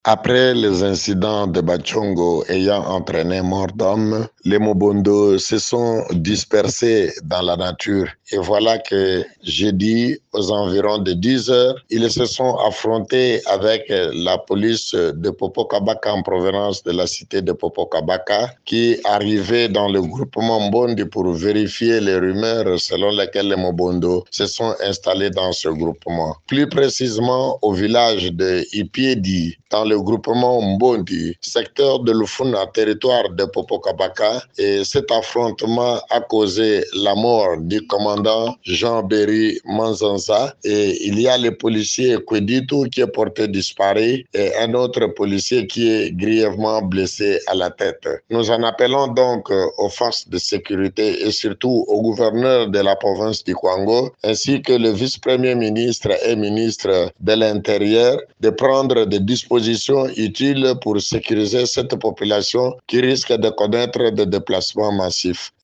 Vous pouvez suivre son plaidoyer dans cet extrait :